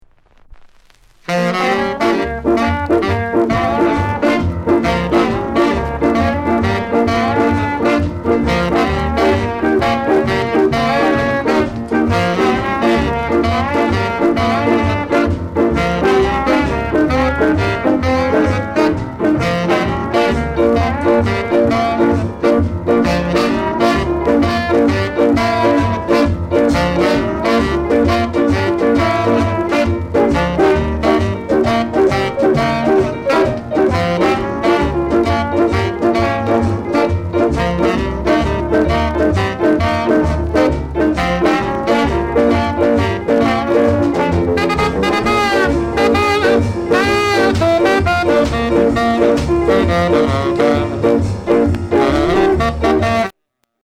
NICE SHUFFLE INST